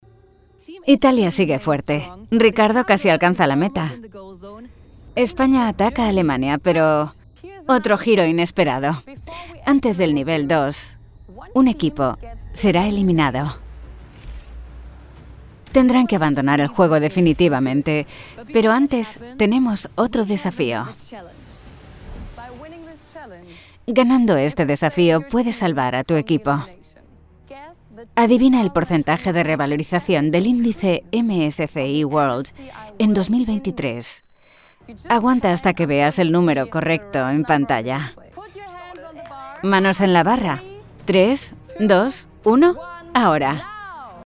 Locutora en español castellano en línea con fluidez en inglés.
Cabina de grabación insonorizada (studiobricks)
Mic Neumann U87 Ai